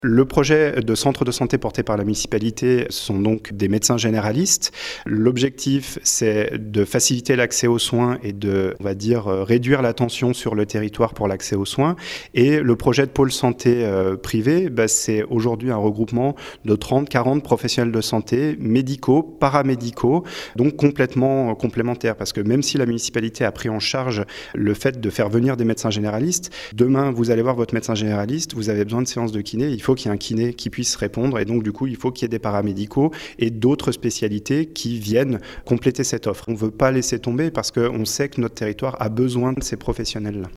Marc Locatelli, maire adjoint à la santé à la Roche sur Foron